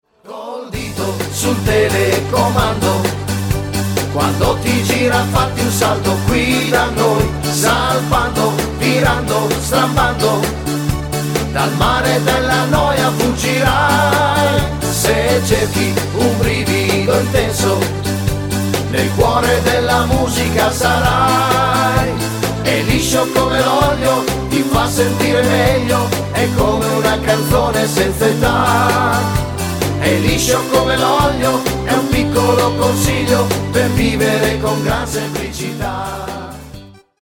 TARANTELLA  (3.58)